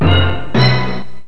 Doki.mp3